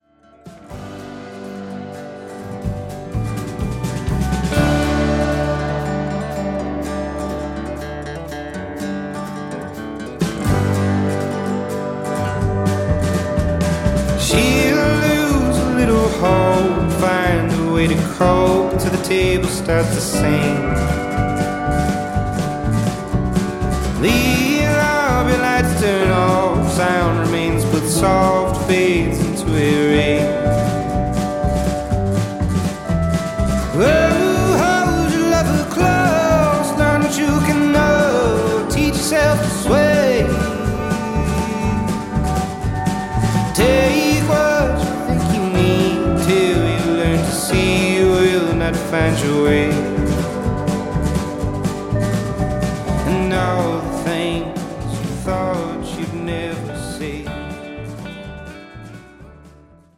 Americana, Folk